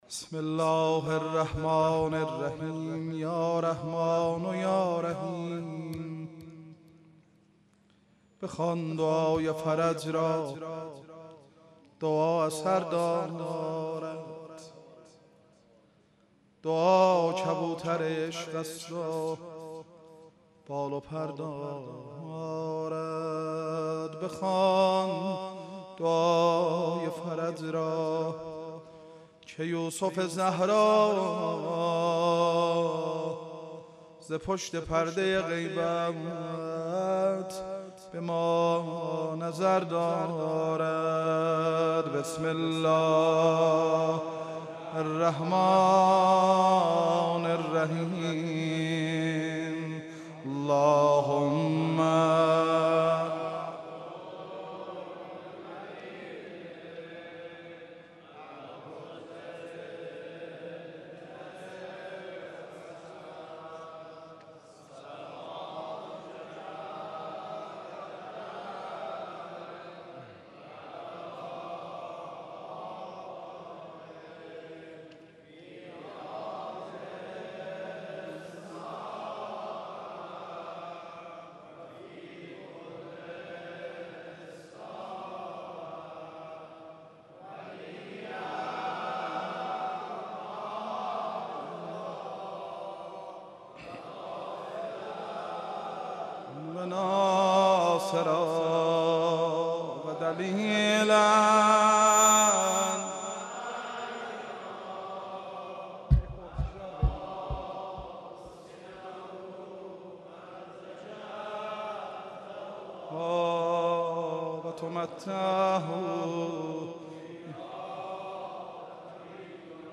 لوه یوخسا آتش دو تارام آتش نیرانونن ،کلیپ، سخنرانی، مداحی، قرآن، نماهنگ، انیمشین، آموزش مداحی، آموزش قرآن،